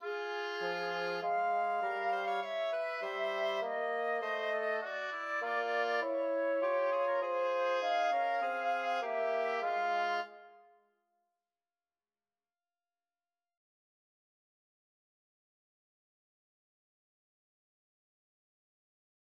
바흐의 3성부 인벤션 F단조는 세 개의 독립적인 선율을 결합한다.
바흐 3성부 인벤션 BWV 795, 7-9 마디